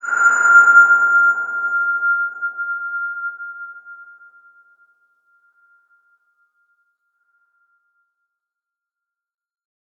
X_BasicBells-F4-pp.wav